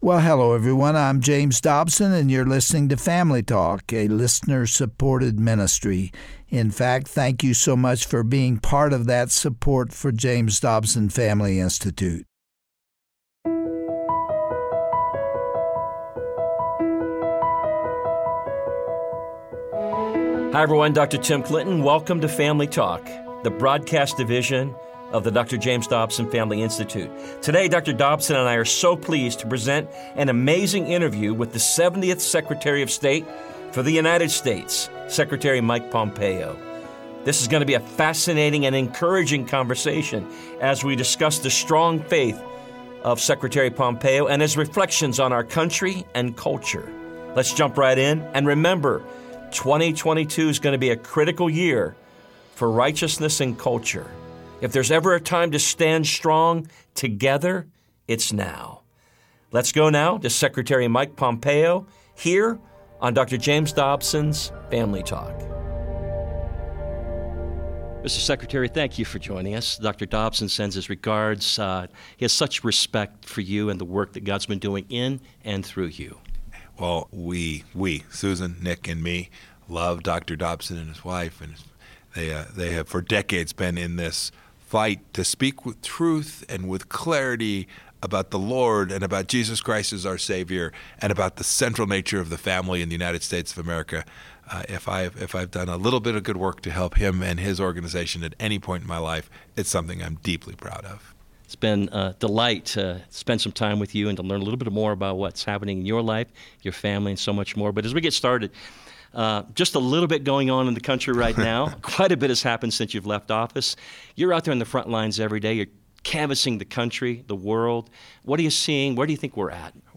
exclusive one-on-one interview